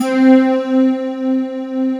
Index of /90_sSampleCDs/Big Fish Audio - Synth City/CD1/Partition B/05-SYNTHLEAD